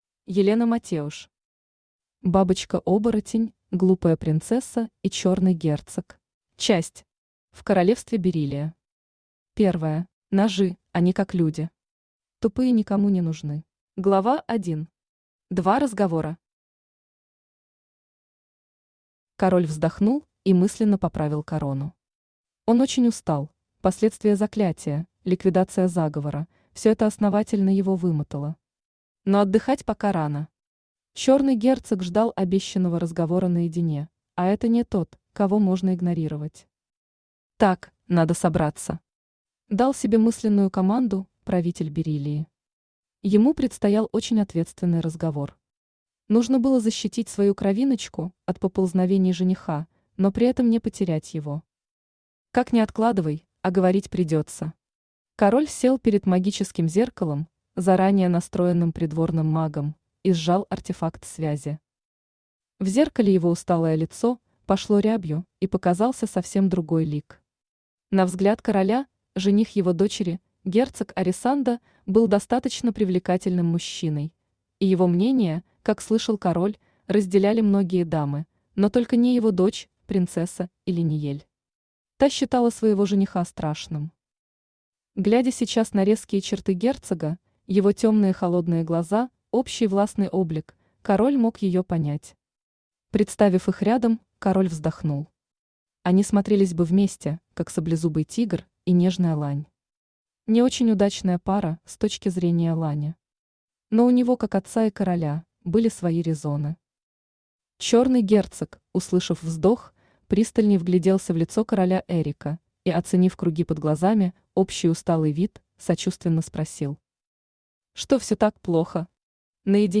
Аудиокнига Бабочка-оборотень, глупая принцесса и Чёрный Герцог | Библиотека аудиокниг
Aудиокнига Бабочка-оборотень, глупая принцесса и Чёрный Герцог Автор Елена Матеуш Читает аудиокнигу Авточтец ЛитРес.